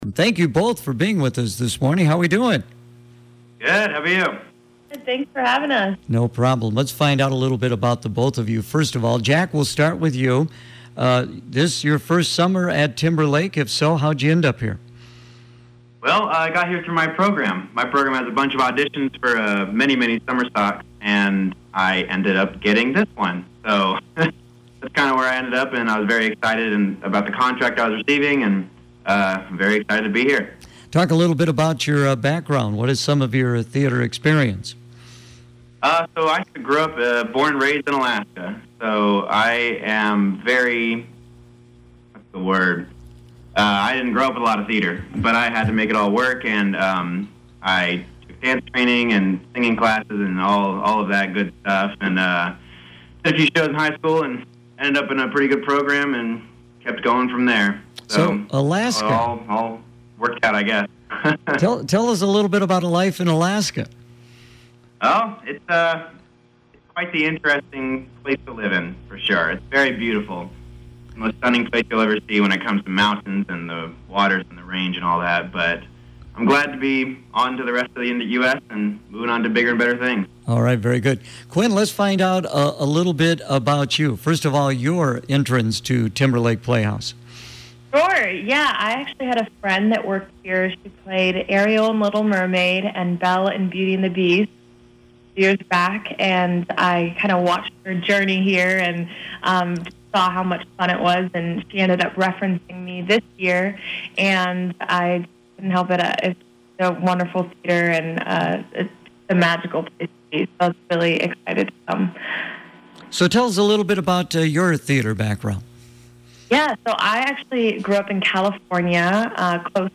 Timberlake Interview 8-4-21